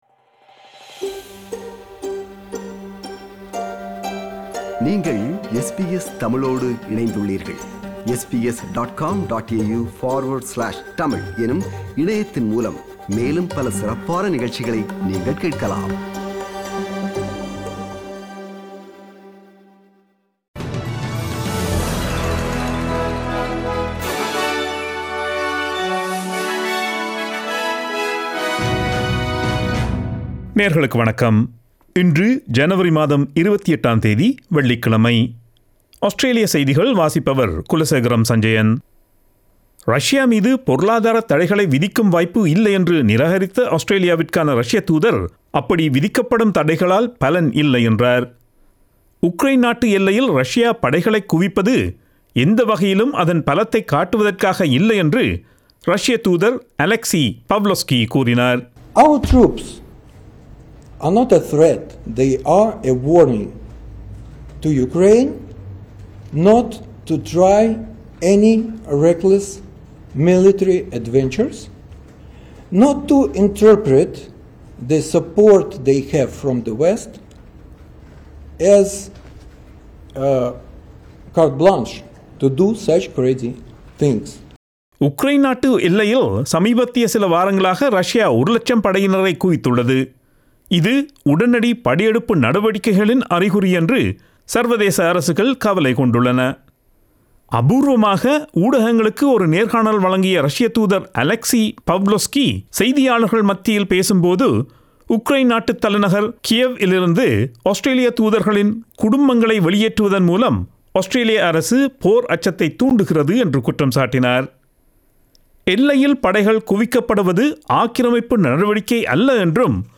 Australian news bulletin for Friday 28 January 2022.